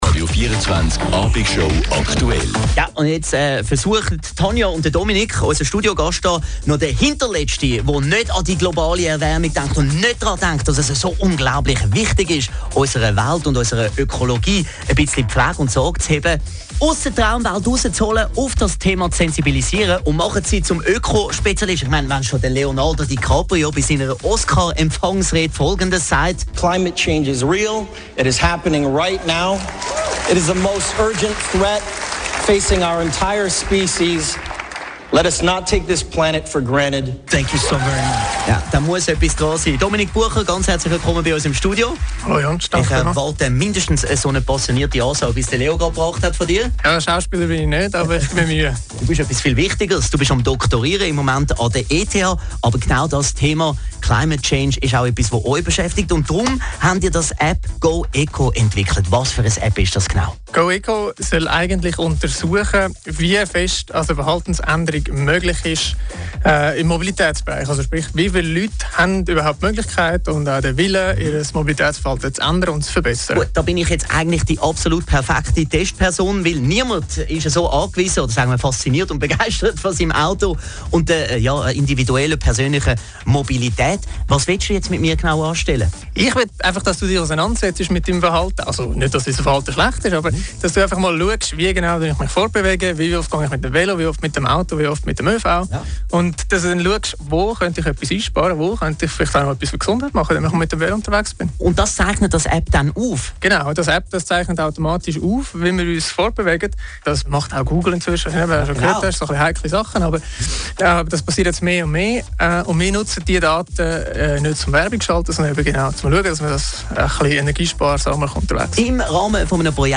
radio24-abig-show-aktuell-go-eco-was-kann-die-neue-app.mp3